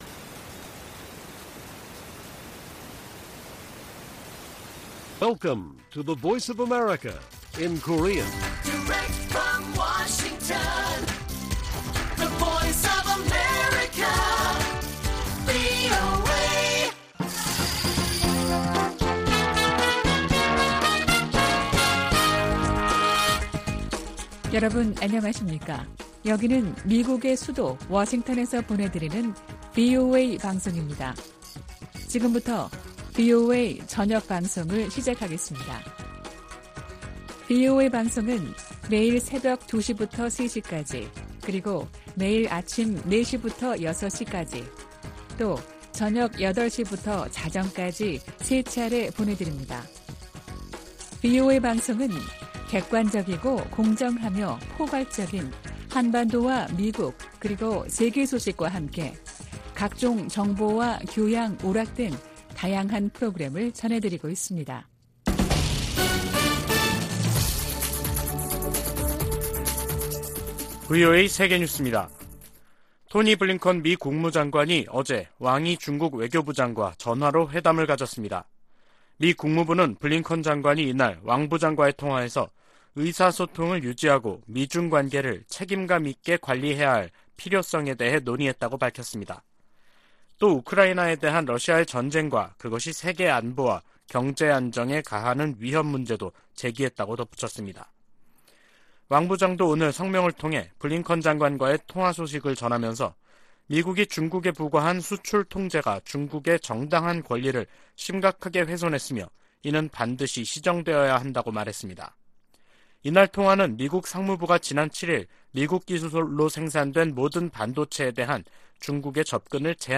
VOA 한국어 간판 뉴스 프로그램 '뉴스 투데이', 2022년 10월 31일 1부 방송입니다. 미국 국무부가 ‘한반도의 완전한 비핵화’에 대한 의지를 확인하면서 북한의 대화 복귀를 거듭 촉구했습니다. 미국과 한국의 최신 군용기들이 대거 참여한 가운데 한반도 상공에서 펼쳐지는 연합 공중훈련 비질런스 스톰이 31일 시작됐습니다.